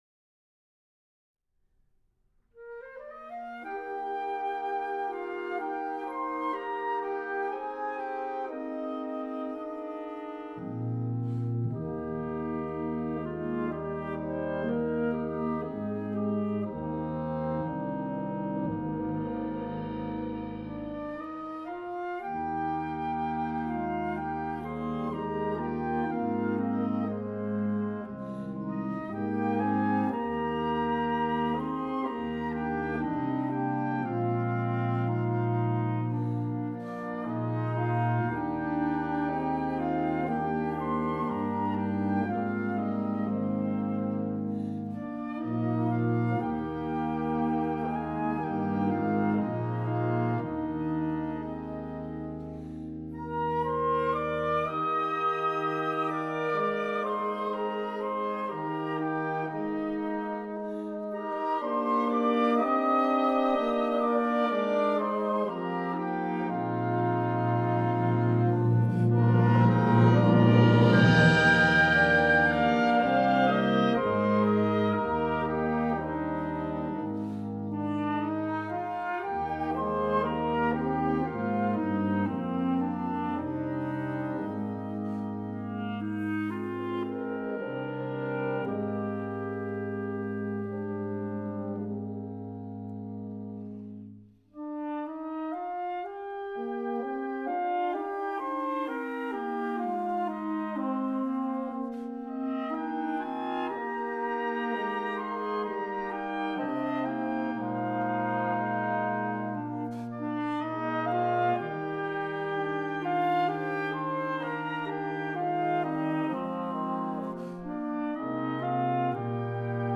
Partitions pour ensemble flexible, 6-voix + percussion.